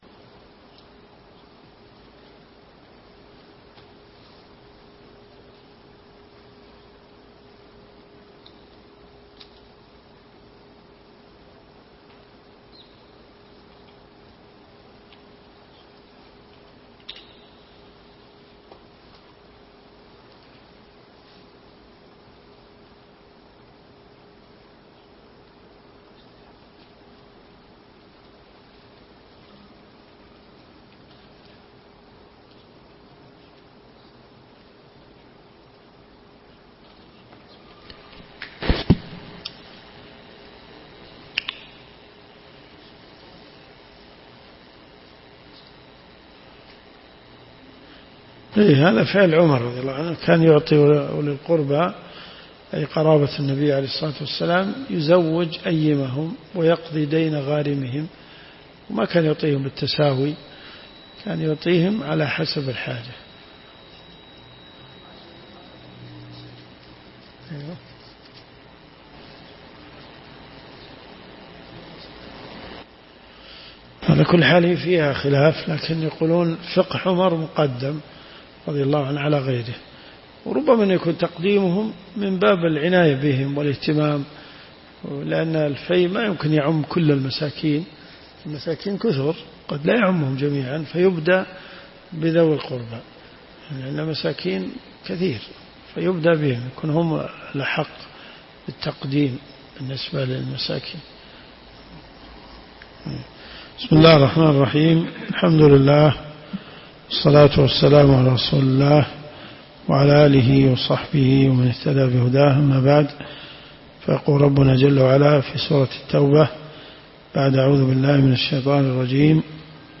دروس صوتيه ومرئية تقام في جامع الحمدان بالرياض
تفسير القران . سورة التوبة . من آية 12 -إلى- آية 15 .